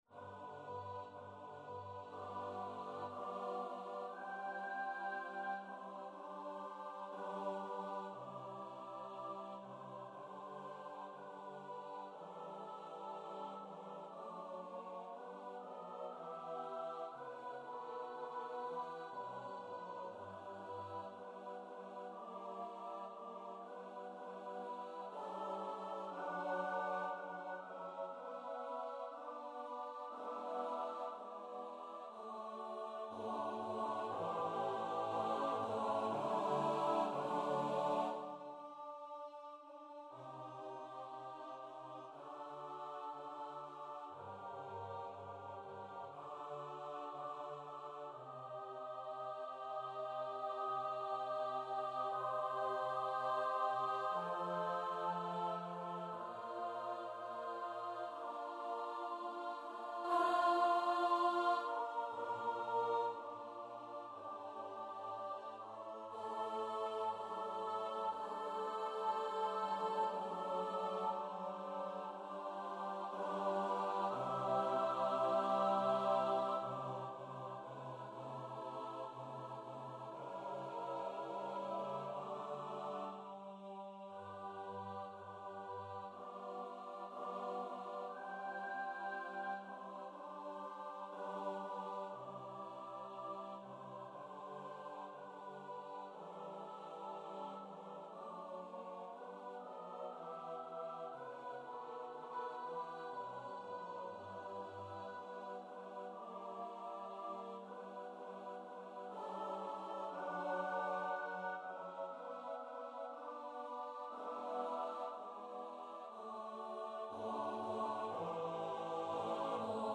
Number of voices: 8vv Voicing: SATB.SATB Genre: Sacred, Motet
Language: German Instruments: A cappella